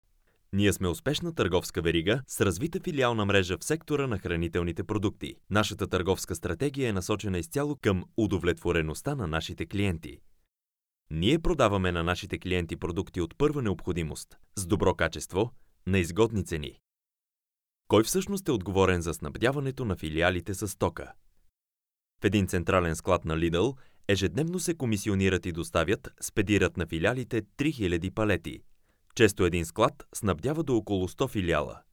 Professioneller bulgarischer Sprecher für TV / Rundfunk / Industrie.
Sprechprobe: Werbung (Muttersprache):
bulgarian voice over artist